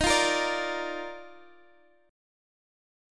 D#M7sus2 chord